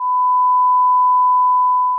Sound 2 – Sinusoid 1000Hz (lower Amplitude)